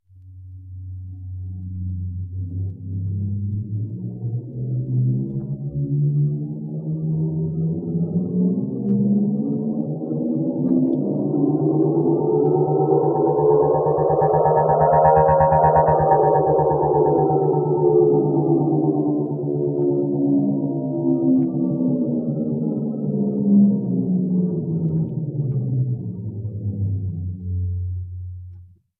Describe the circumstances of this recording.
A recording from an LP : Method : first Noise Reduction, than Single Click Removal.